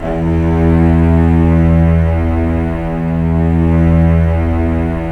Index of /90_sSampleCDs/Roland L-CD702/VOL-1/STR_Symphonic/STR_Symph.+attak